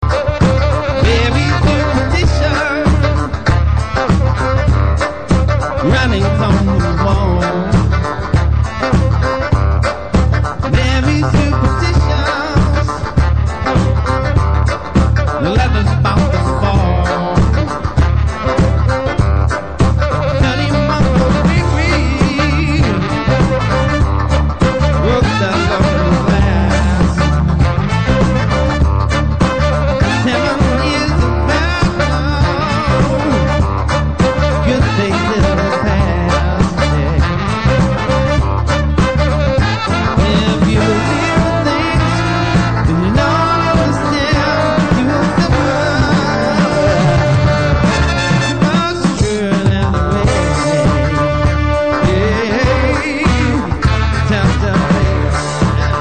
Solo Entertainer - Duo - Trio - Band
He plays Piano/Keyboard, Guitar, Sax, Clarinet, Flute, Percussion, Fiddle/Violin.
Performing with the best musicians available and creates a unique smooth funky dance sound.